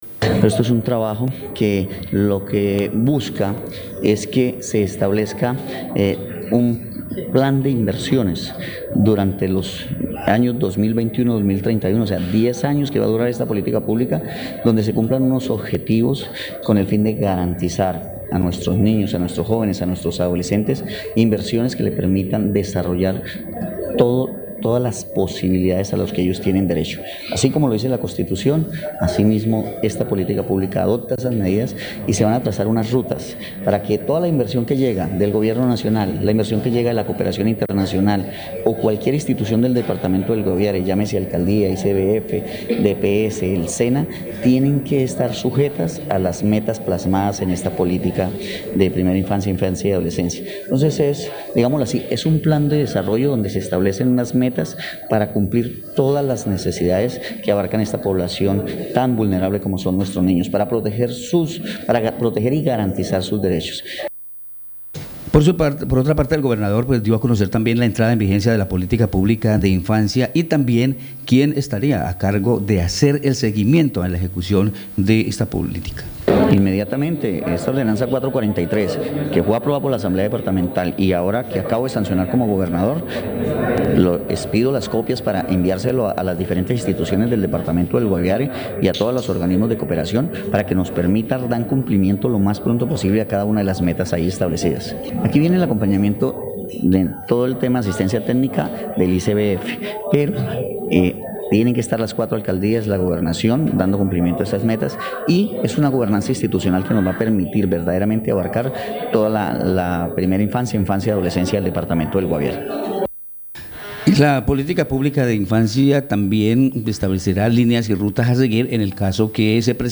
Escuche a Heydeer Palacio, gobernador del Guaviare.
Escuche a Joaquín Mendieta, director Regional ICBF Guaviare.